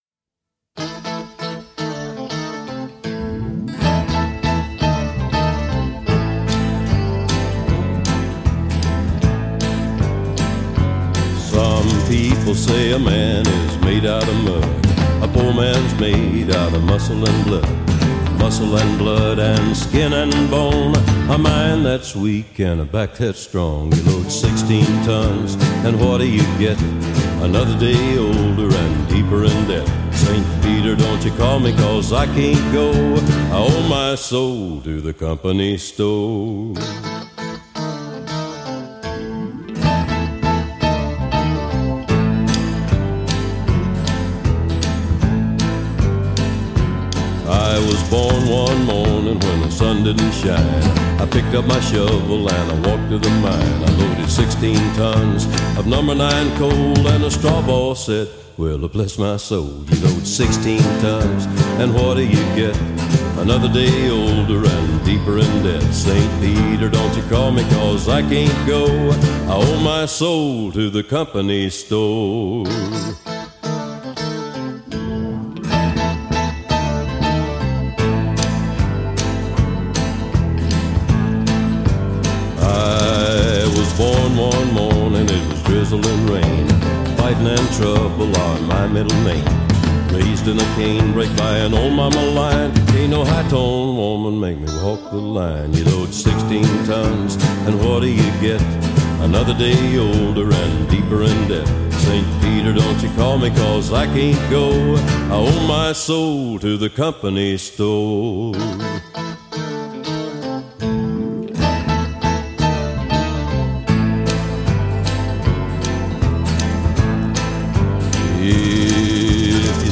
country کانتری